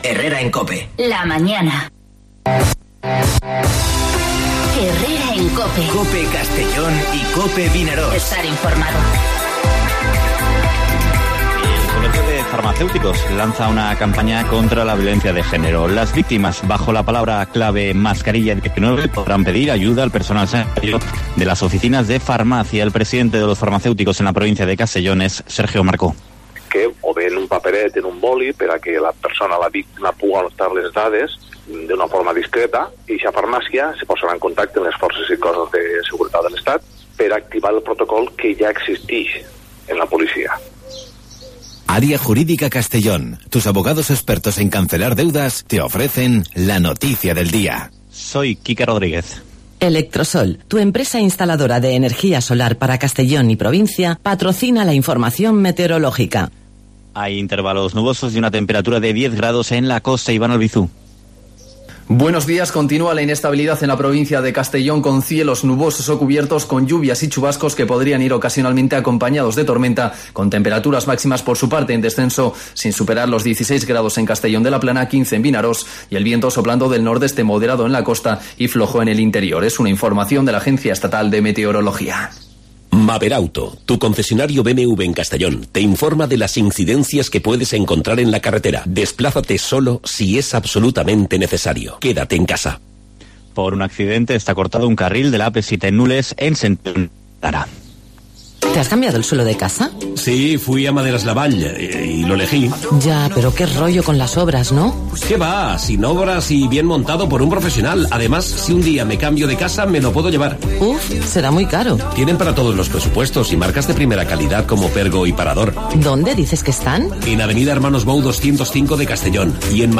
Informativo Herrera en COPE Castellón (02/04/2020)